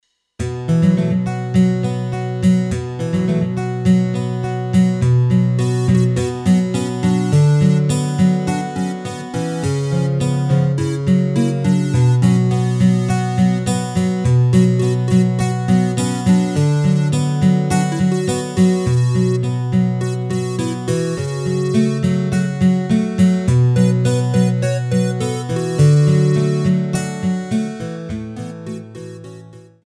Rubrika: Folk, Country
HUDEBNÍ PODKLADY V AUDIO A VIDEO SOUBORECH